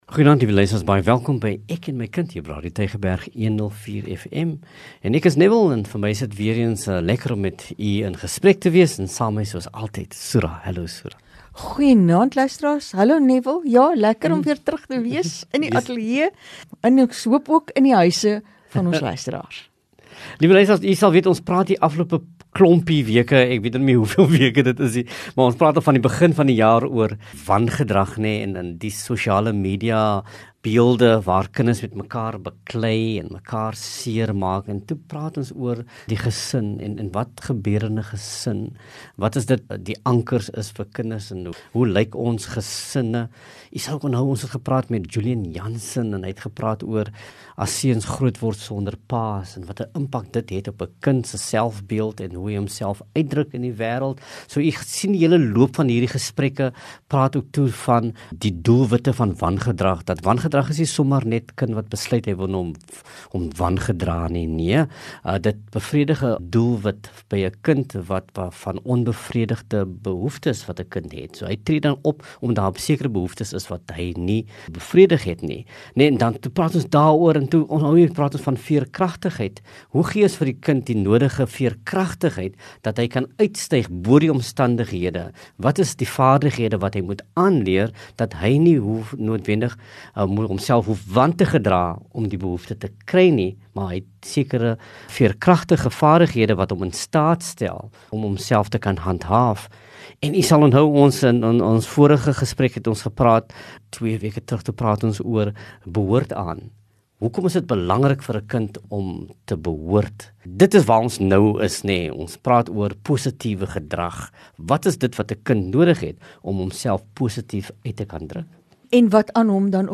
“Ek en my kind” is gespreksprogram wat vanuit ‘n onderwysperspektief ouerleiding bied ten opsigte van kinderontwikkeling en kindersorg. Dit gee ouers perspektief oor Onderwyssake en brandpunte wat impak kan hê op hul rol en verantwoordelikheid ten opsigte van hul kind se welstand in die klaskamer en op die speelgrond.